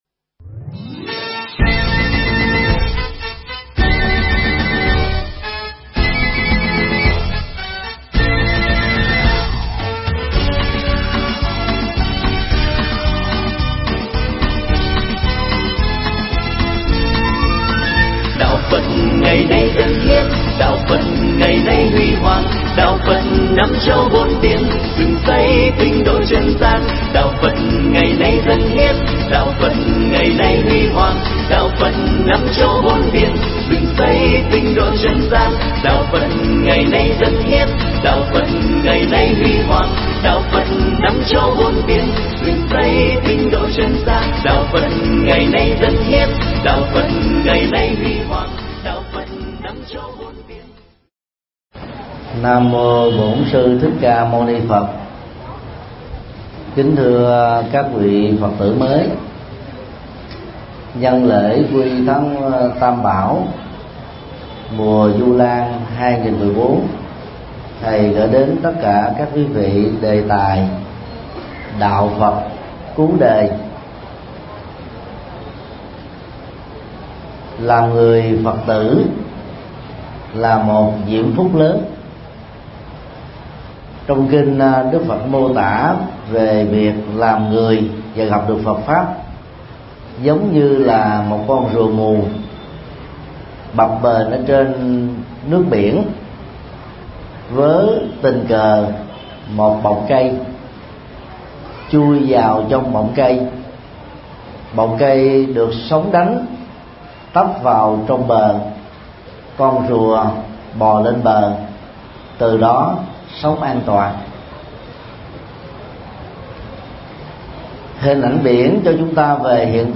Mp3 Pháp Thoại Đạo Phật cứu đời
Giảng tại chùa Giác Ngộ